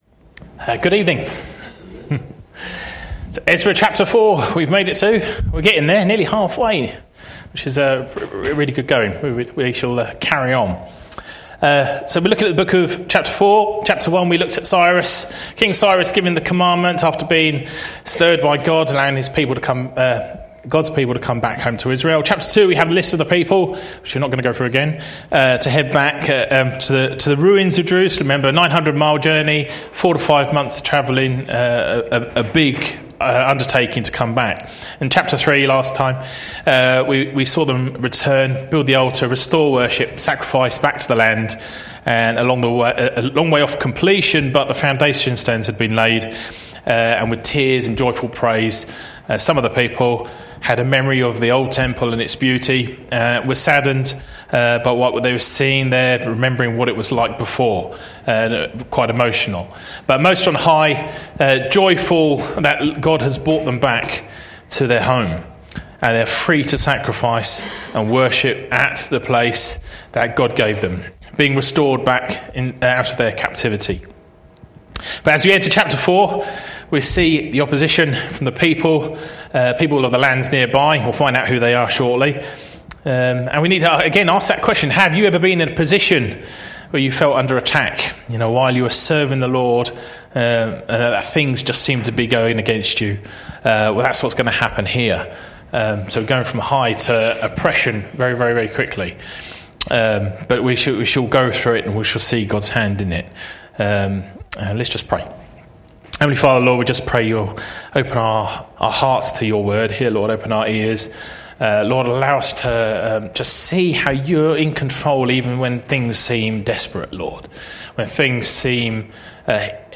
Share this: Share on X (Opens in new window) X Share on Facebook (Opens in new window) Facebook Share on WhatsApp (Opens in new window) WhatsApp Series: Sunday evening studies Tagged with Verse by verse